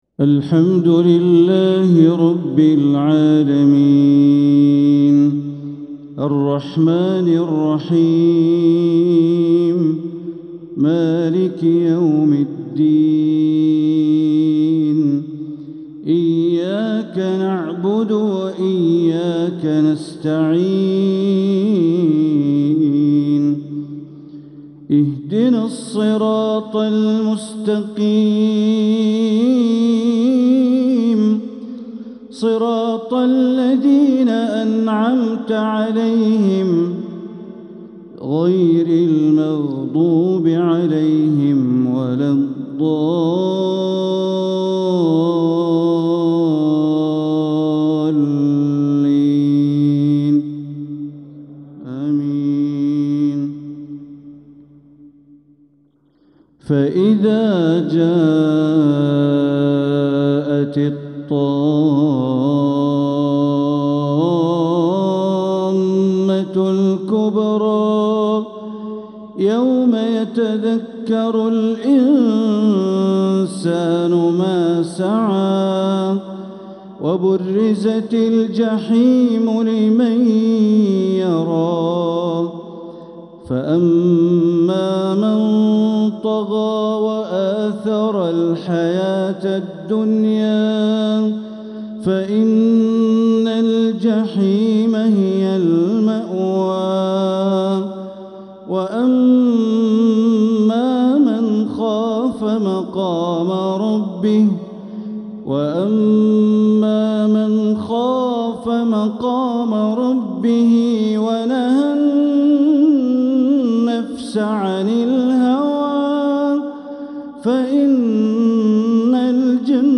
Isha prayer from surat An-Naziat and Abasa 6-2-2025 > 1446 > Prayers - Bandar Baleela Recitations